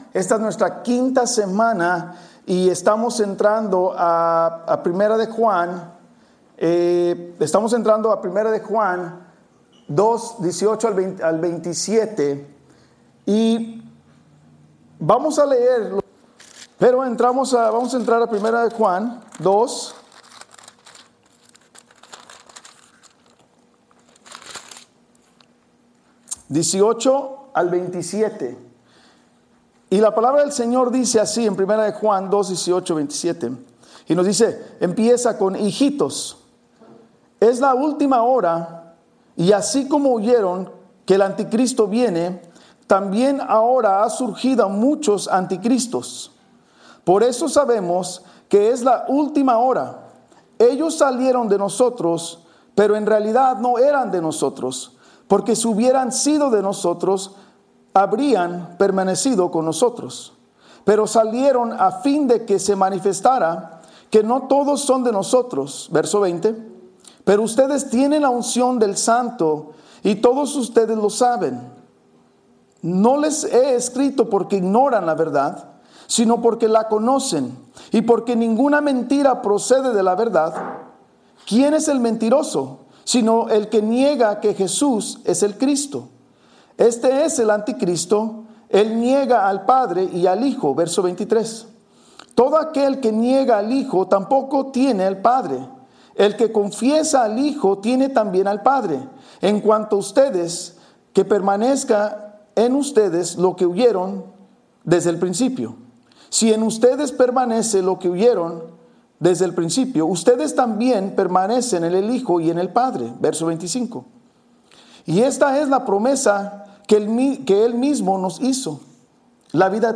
Estudio biblico verso por verso